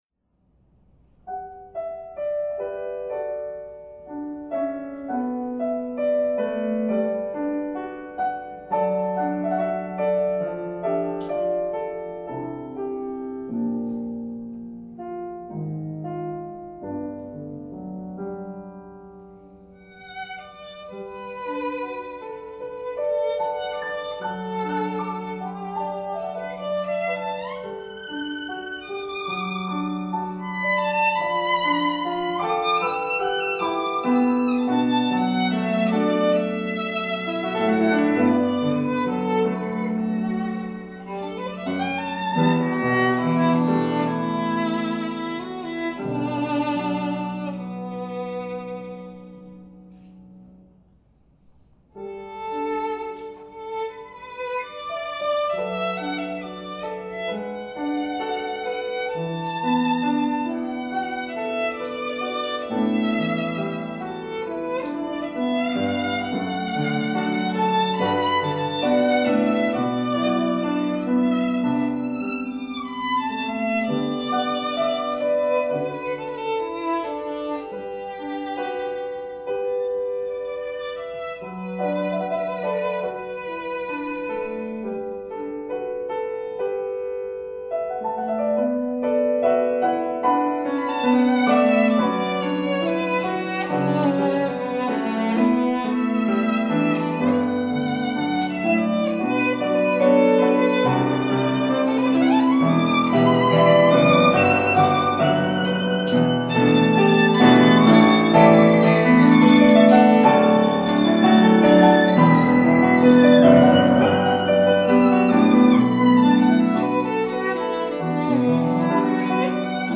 for Violin and Piano
This recording is the live performance from:
violin
piano